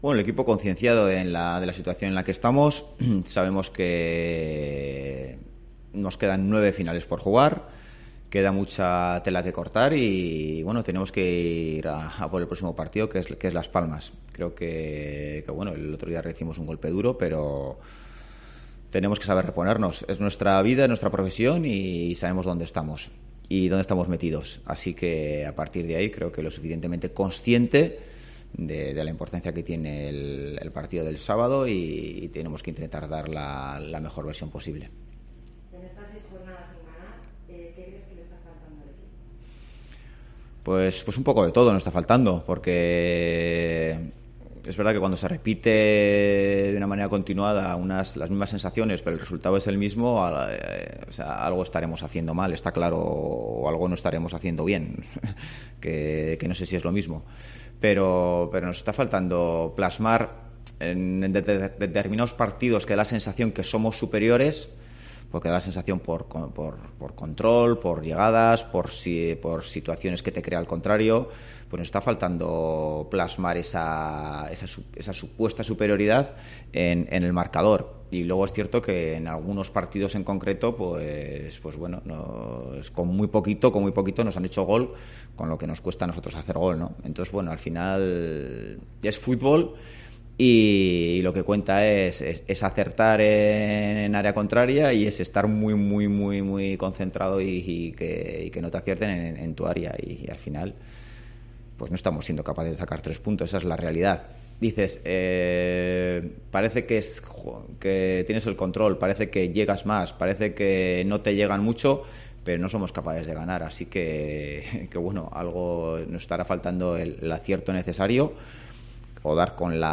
Rueda de prensa de Ziganda (previa Oviedo-UD Las Palmas)